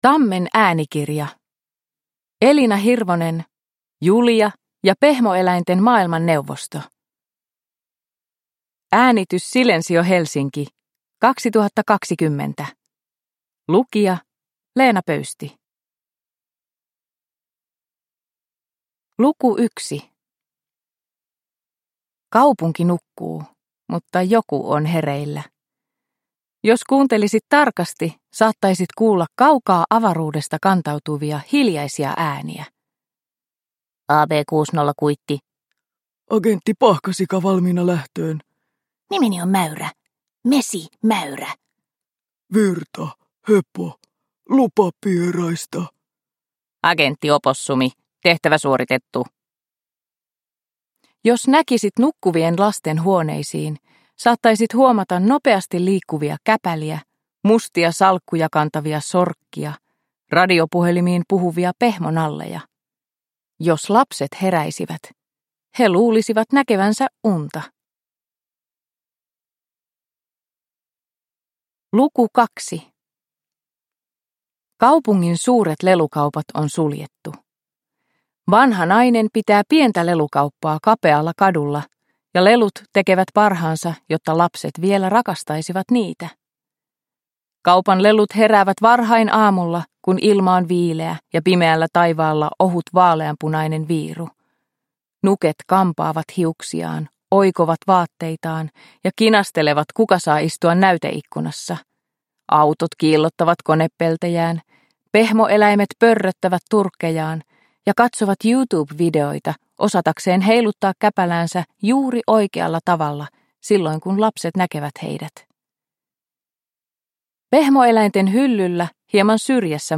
Uppläsare: Leena Pöysti